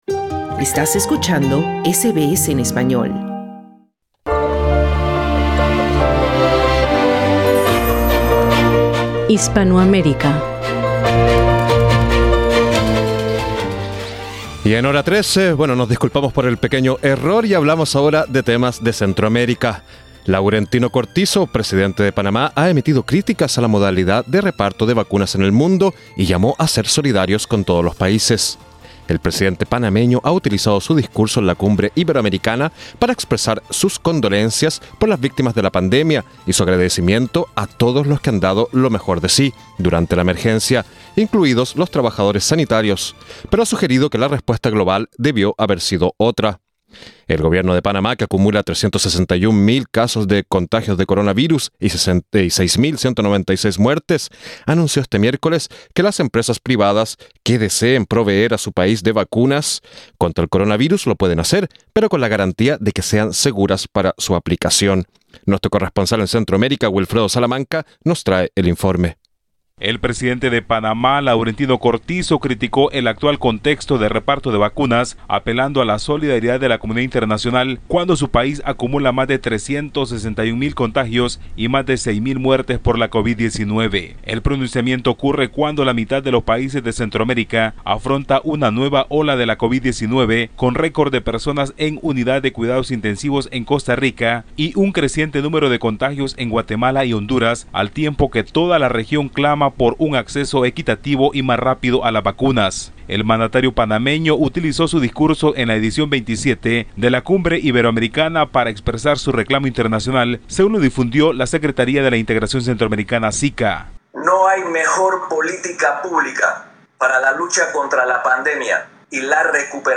Escucha el informe de nuestro corresponsal en Centroamérica